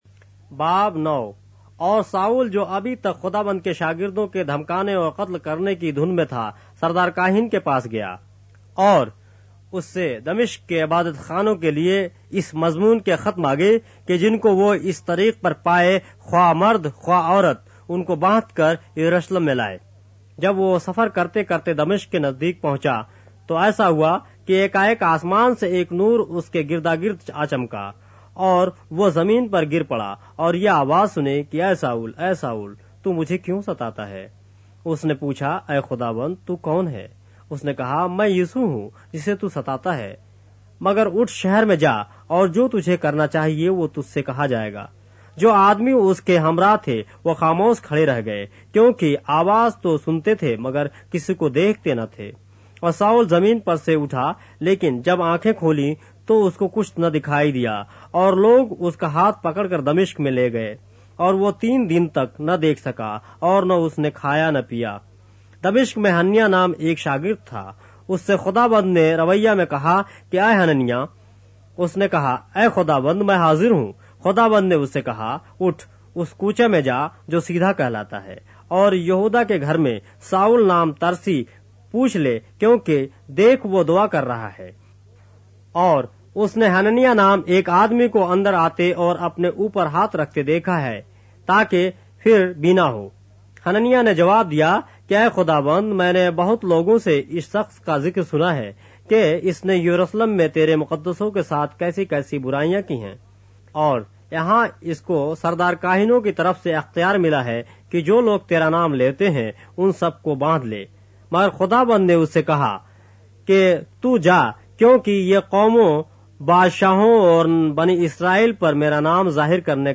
اردو بائبل کے باب - آڈیو روایت کے ساتھ - Acts, chapter 9 of the Holy Bible in Urdu